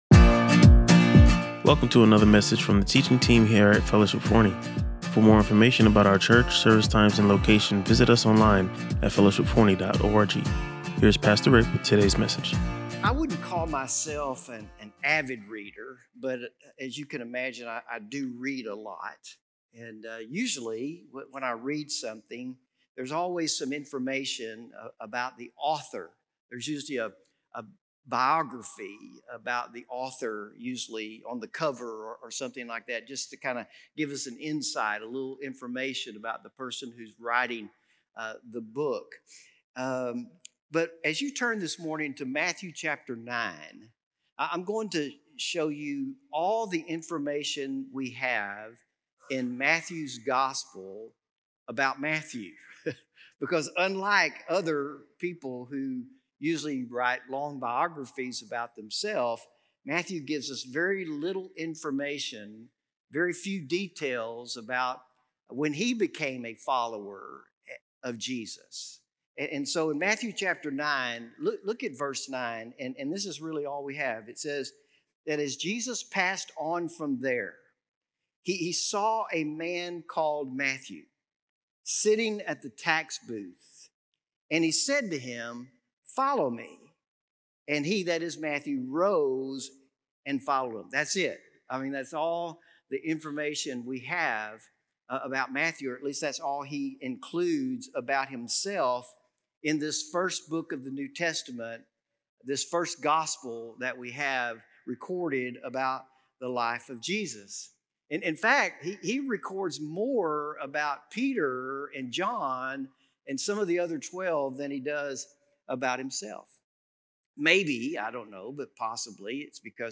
To explore more about this inspiring message, listen to or watch the full sermon.